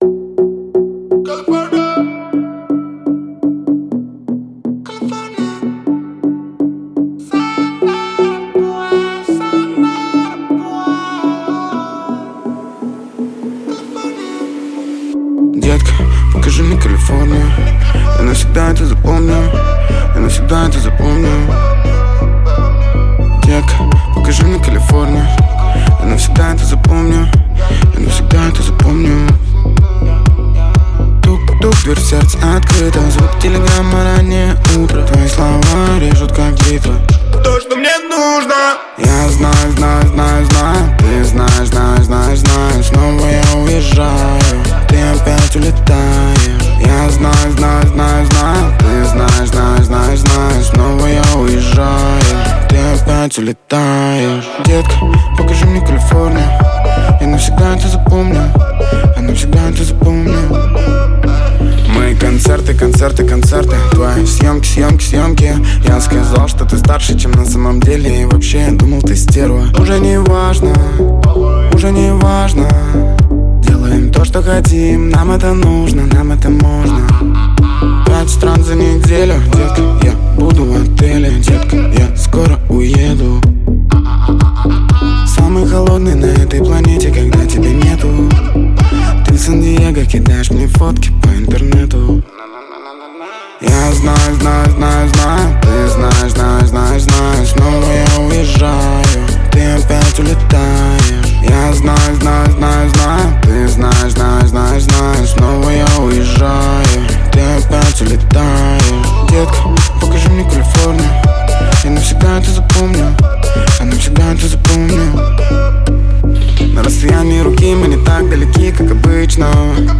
Хип-хоп
Жанр: Хип-хоп / В машину